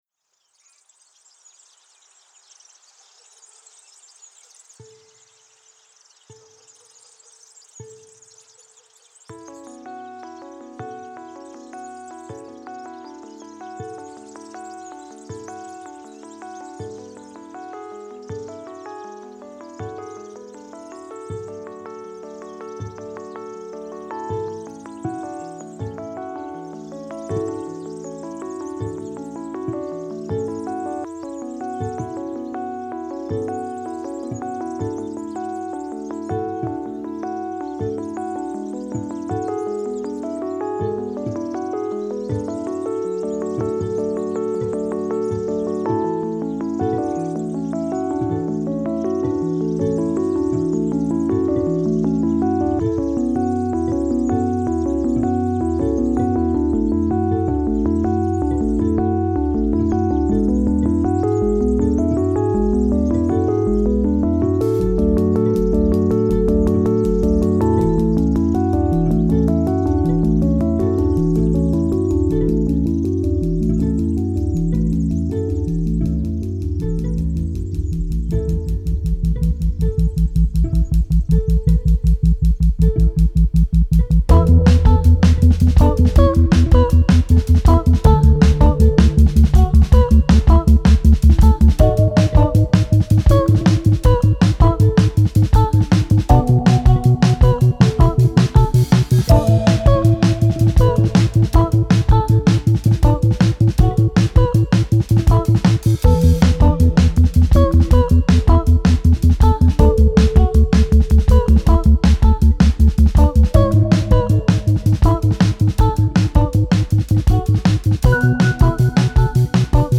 Genres: Experimental, Instrumental